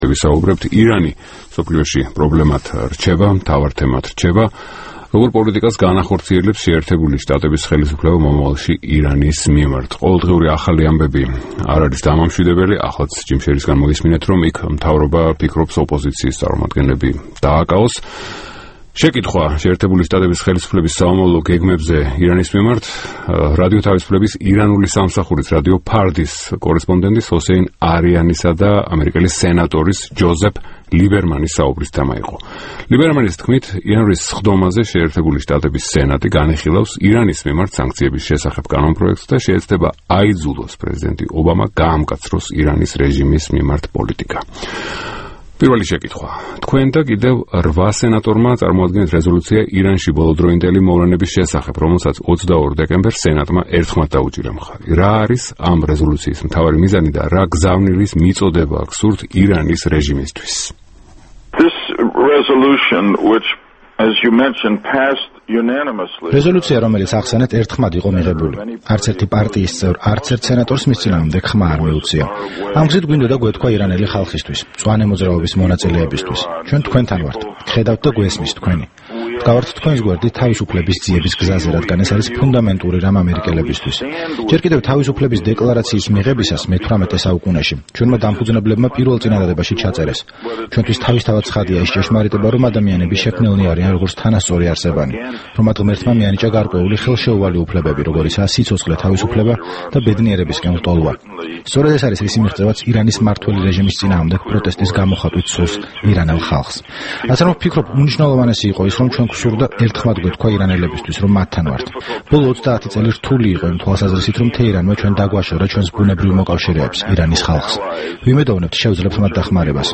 ინტერვიუ სენატორ ჯოზეფ ლიბერმანთან